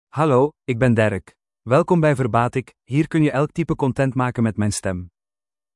Derek — Male Dutch (Belgium) AI Voice | TTS, Voice Cloning & Video | Verbatik AI
MaleDutch (Belgium)
Voice sample
Male